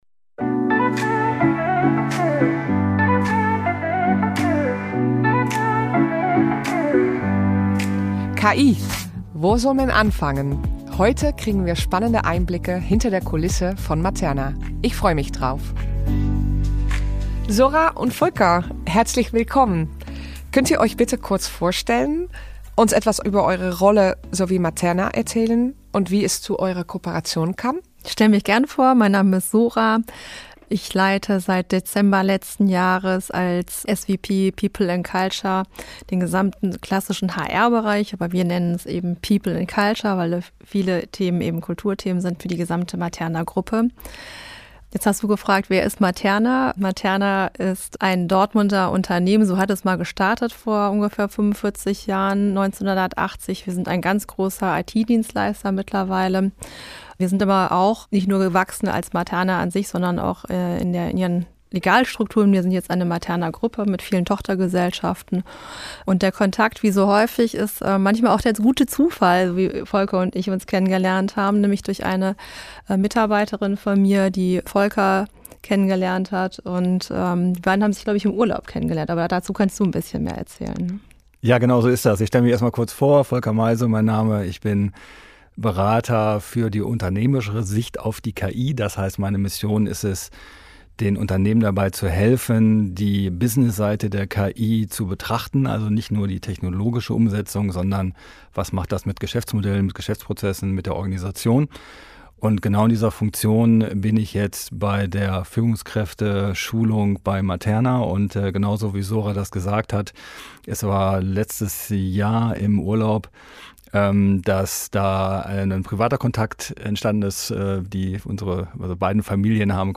bringt dieses Gespräch Klarheit in ein komplexes Thema. Wir beleuchten den gesamten Weg der KI-Einführung – von der Strategie bis zur Umsetzung – und zeigen, warum Unternehmenskultur der wahre Motor der Transformation ist.